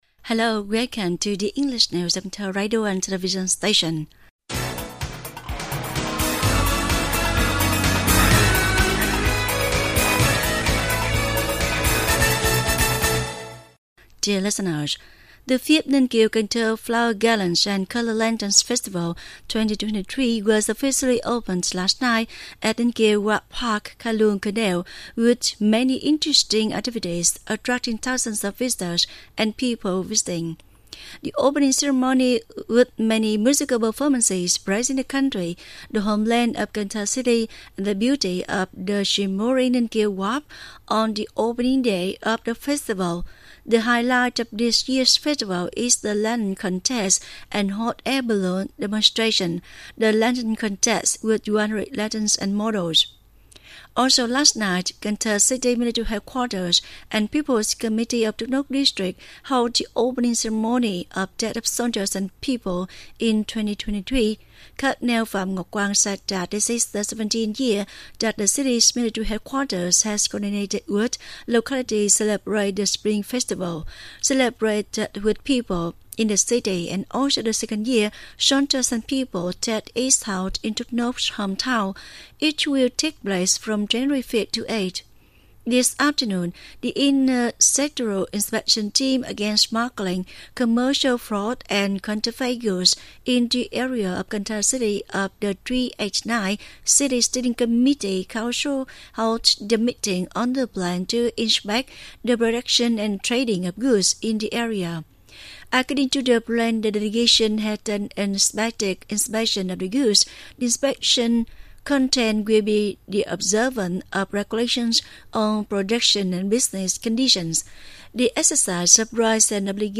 Bản tin tiếng Anh 6/1/2023